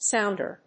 音節sóund・er 発音記号・読み方
/ˈsaʊndɝ(米国英語), ˈsaʊndɜ:(英国英語)/
sounder.mp3